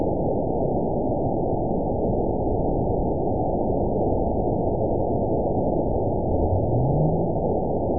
event 920318 date 03/15/24 time 19:07:14 GMT (1 year, 1 month ago) score 9.56 location TSS-AB05 detected by nrw target species NRW annotations +NRW Spectrogram: Frequency (kHz) vs. Time (s) audio not available .wav